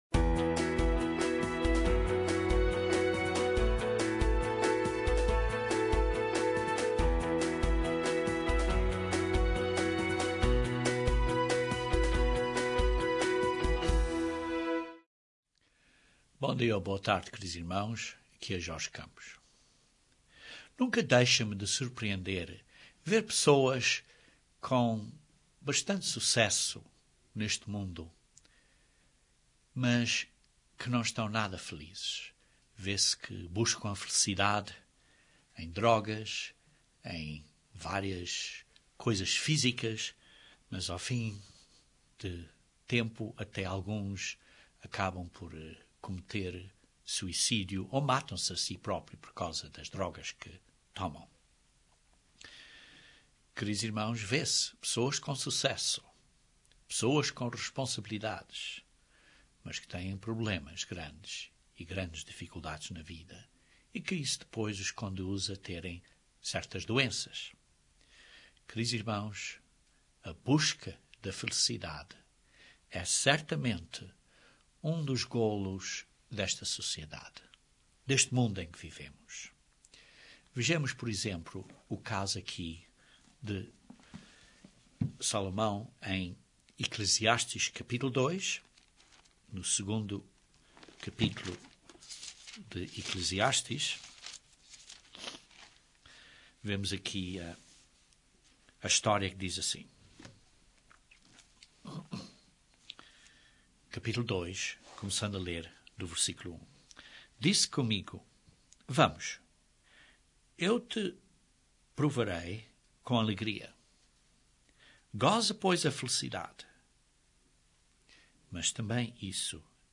Este sermão descreve alguns pontos de desenvolvimento emocional para que possamos ser felizes.